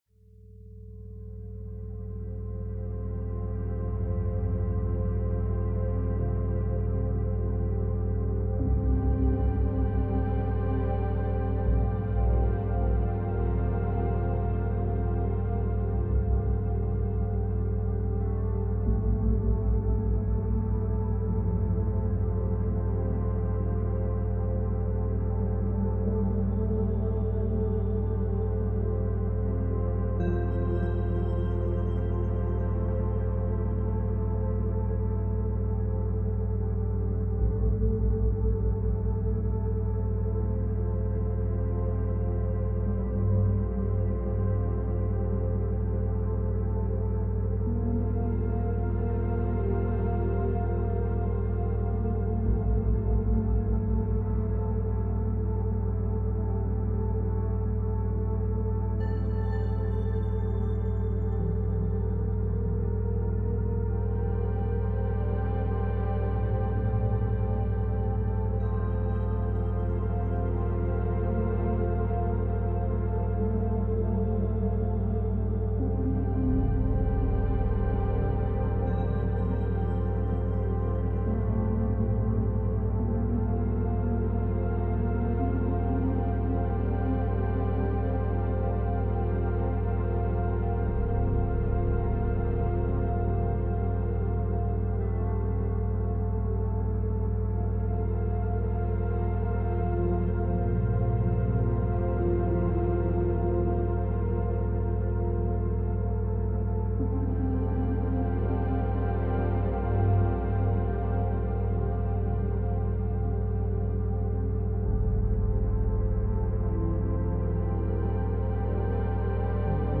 Alpha Waves – 11 Hz for Creative Focus and Relaxation
Study Sounds, Background Sounds